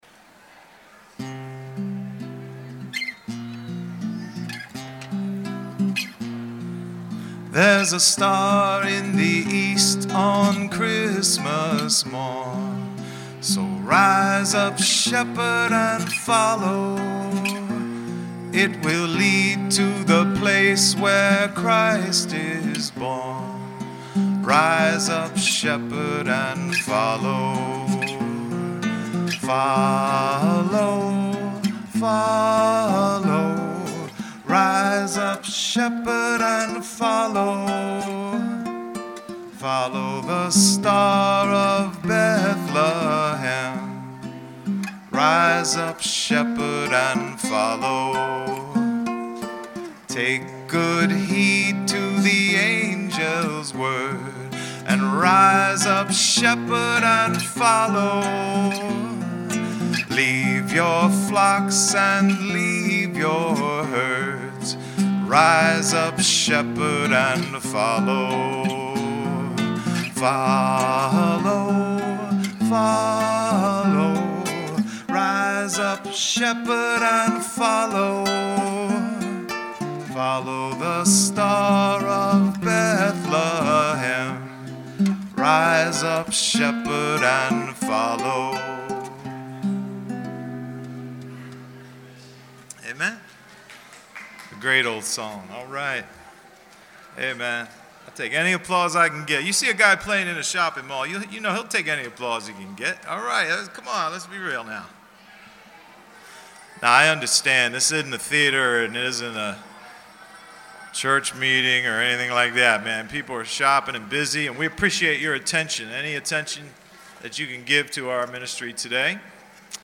same tour at the Cumberland Mall in Vineland, NJ:
Voice and Guitar- Cumberland Mall Vineland, NJ December 15, 2012